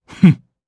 Bernheim-Vox-Laugh_jp.wav